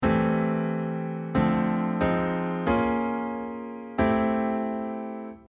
In the context of C major tonality, the target chord is Am7 and I am using minor seventh chords in real parallel motion to make a chromatic approach to it
using parallel motion with target chord and resolving as chromatic approach chord.mp3